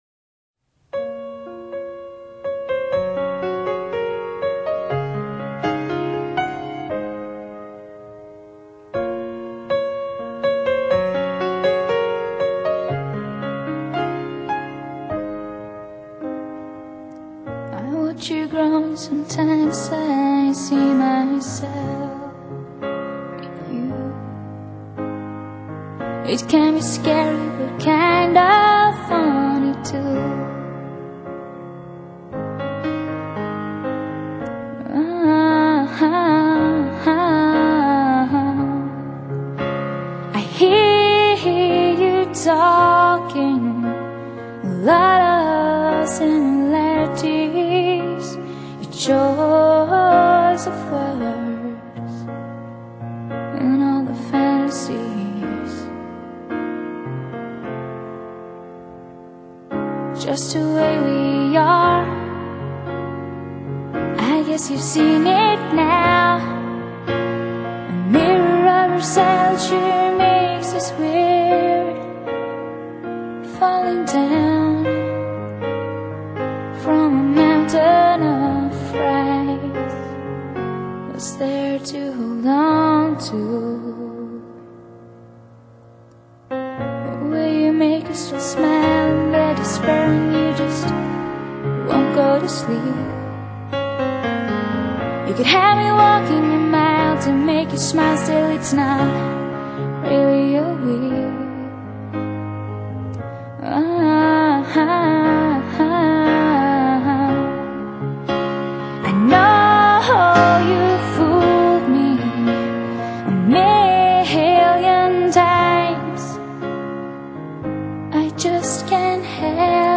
伴随空灵纯净的Piano Solo
整支单曲，背景钢琴无疑是最出彩的，忧伤的气氛不浓，却恰到好处
遗世孤立的钢琴再度将我带回那个没有哀伤，没有战争，没有烦恼的美丽新世界。